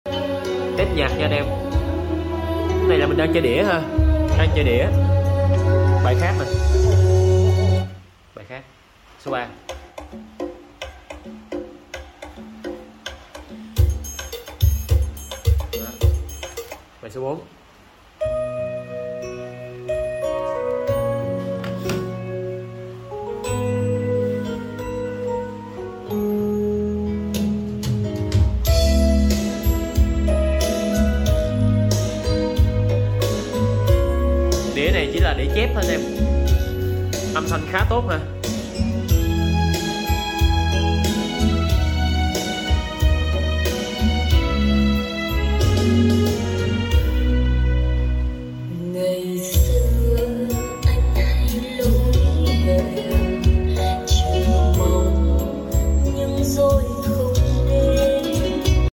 Test âm Thanh Dàn PANASONIC Sound Effects Free Download